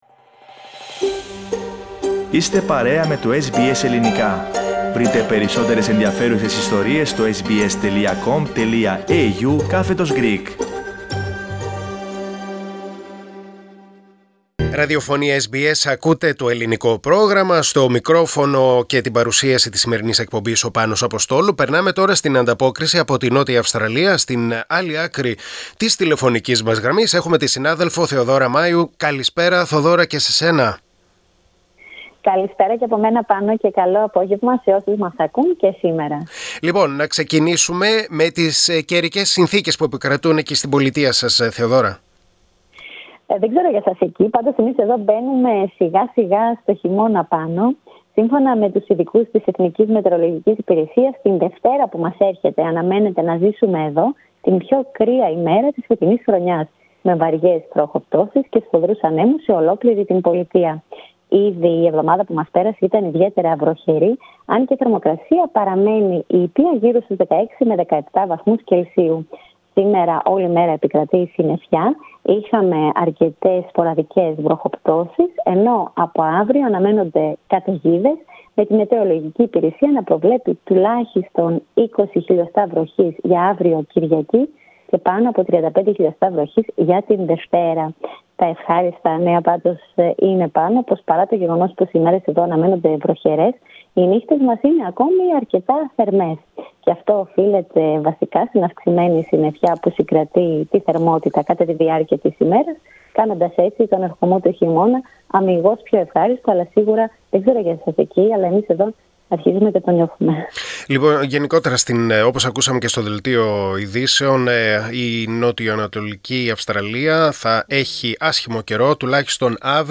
Ακούστε την εβδομαδιαία ανταπόκριση από την Αδελαΐδα της Νότιας Αυστραλίας.